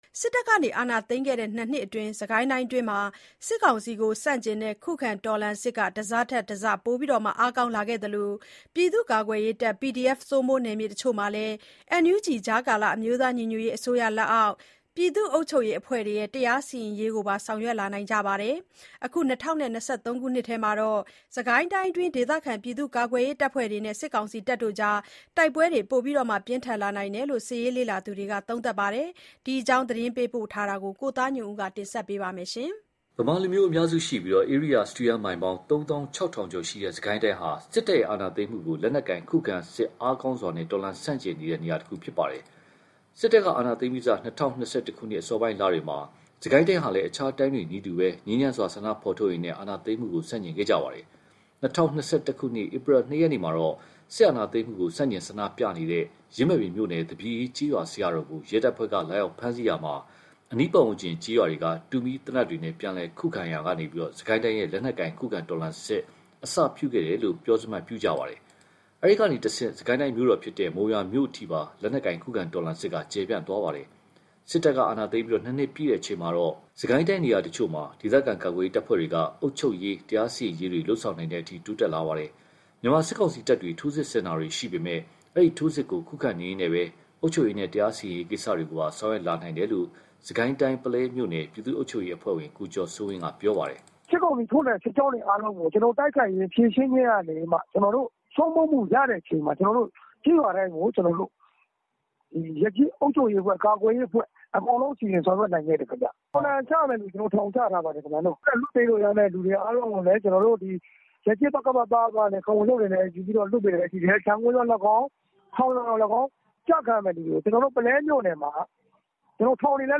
အာဏာသိမ်းပြီးနှစ်နှစ်ကြာ စစ်ကိုင်းဒေသ ပြည်သူ့အုပ်ချုပ်ရေး (အာဏာသိမ်းနှစ်နှစ်ပြည့် အထူးသတင်းဆောင်းပါး)